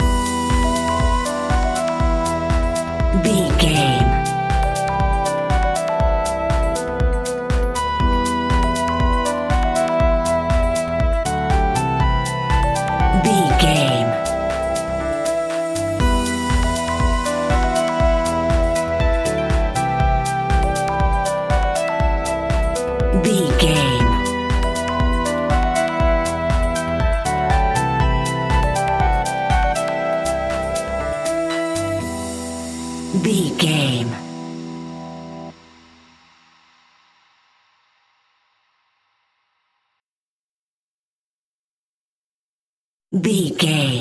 Aeolian/Minor
uplifting
futuristic
driving
energetic
repetitive
bouncy
synthesiser
drum machine
electro house
progressive house
synth bass